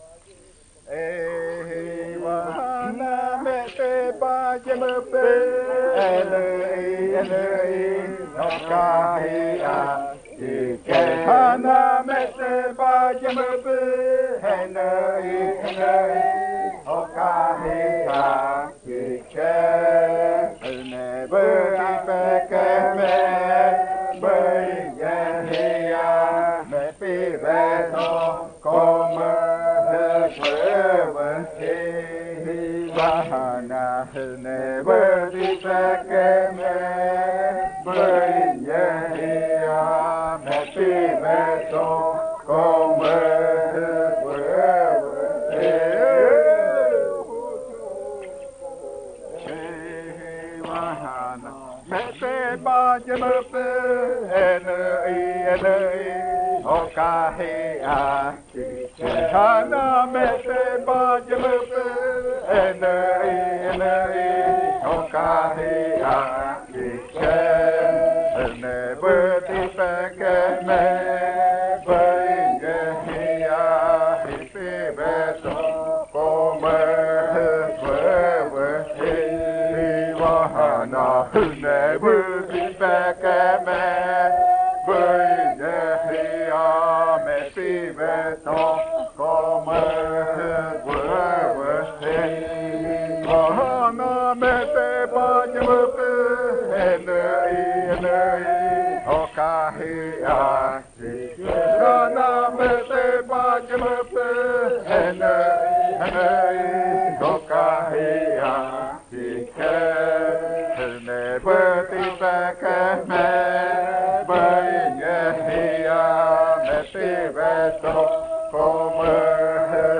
25. Baile de nombramiento. Canto n°23
Puerto Remanso del Tigre, departamento de Amazonas, Colombia